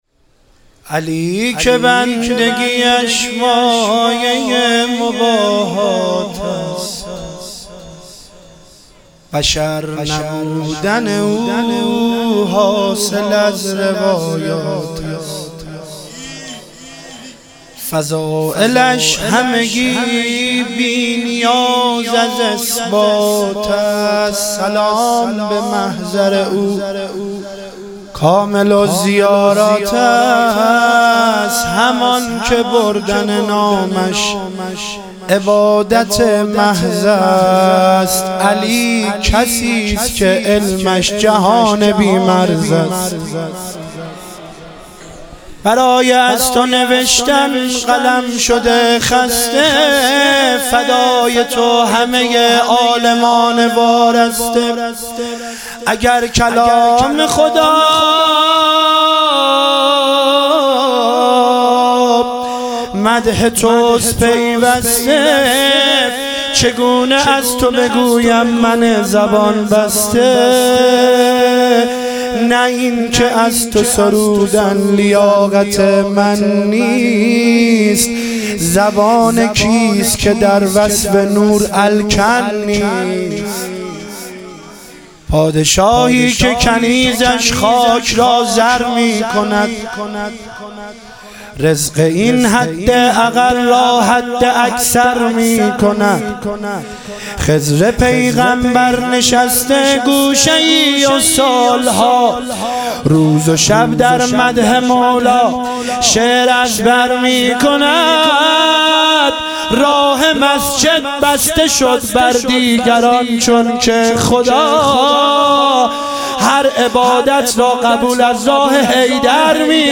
عید سعید غدیر خم - مدح و رجز